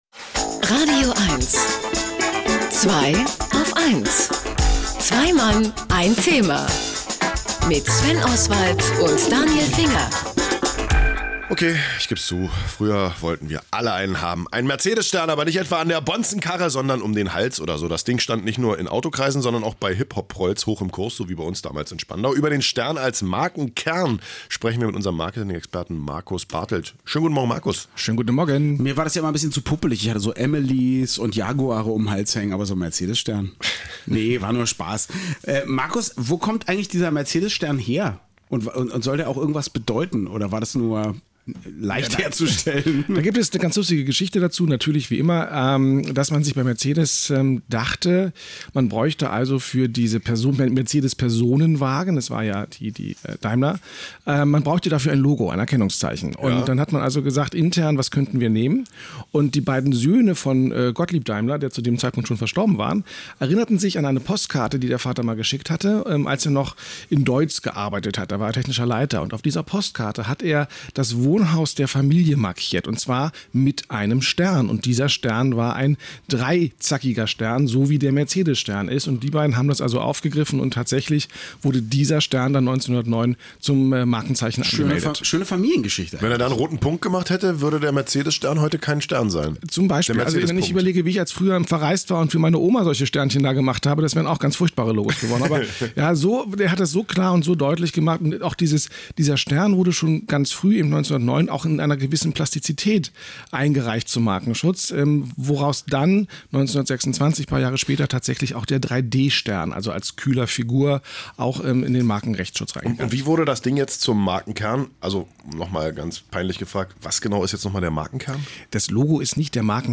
P.S. Hier noch das im Interview erwähnte T-Shirt… 🙂